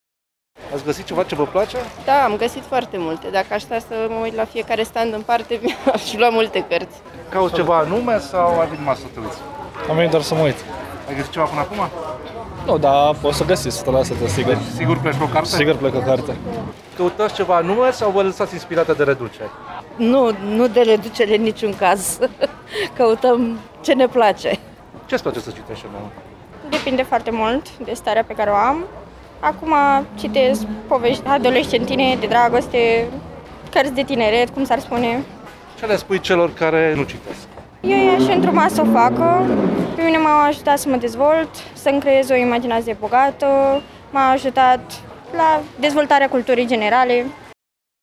A fost forfotă mare și ieri în Piața Sfatului din Brașov la Târgul de Carte Gaudeamus Radio România.